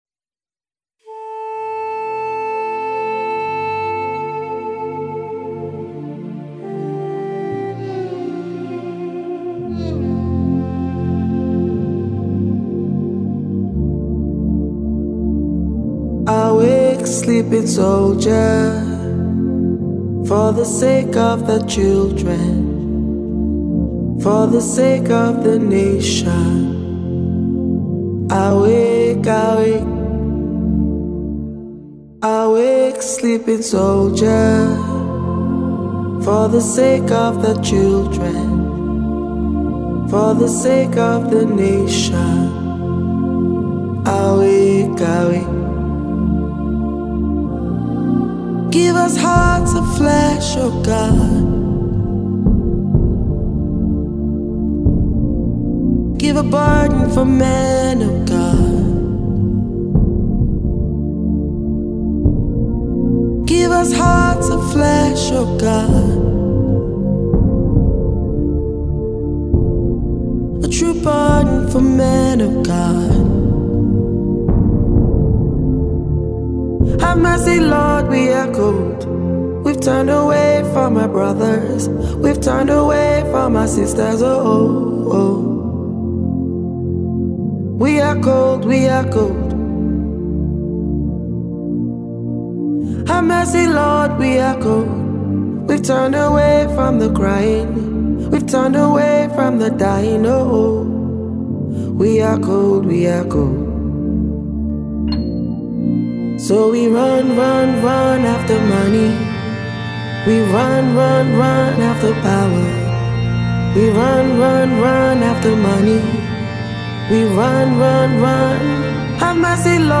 Keys